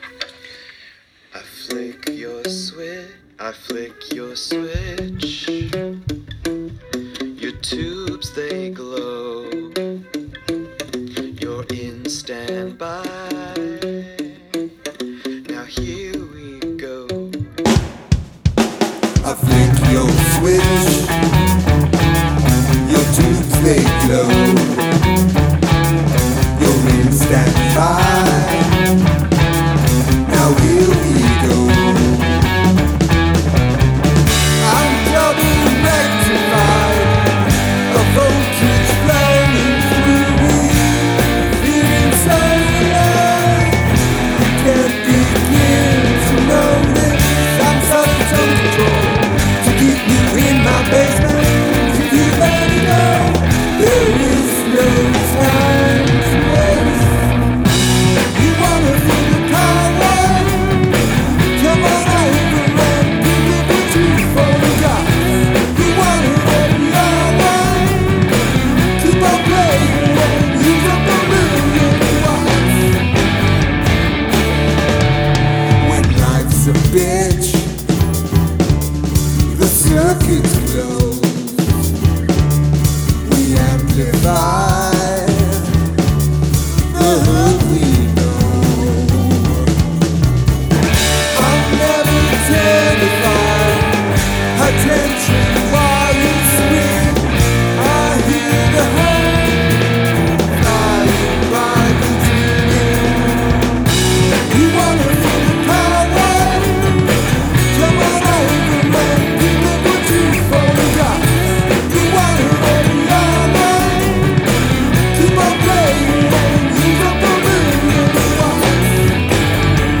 Song must feature an obvious flaw. Flaw cannot be lyrical.